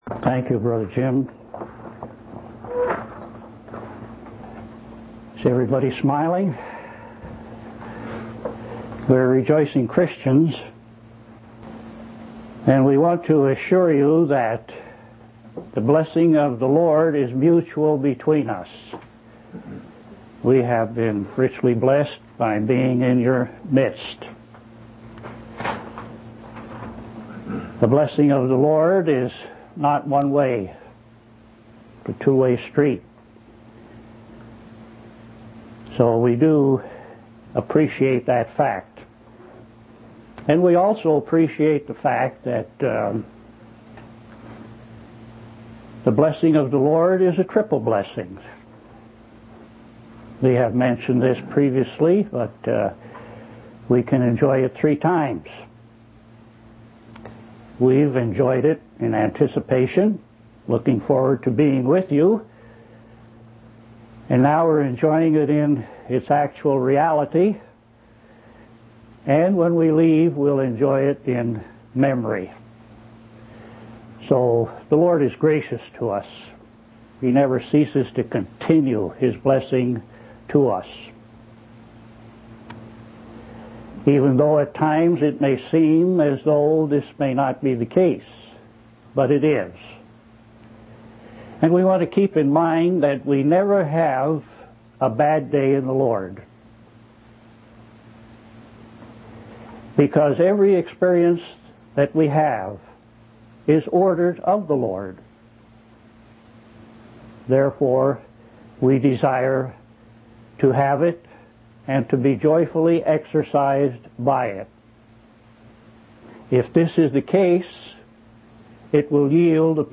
From Type: "Discourse"
Mahomet Convention 1986